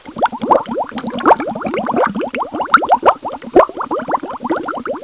Bubles1
BUBLES1.WAV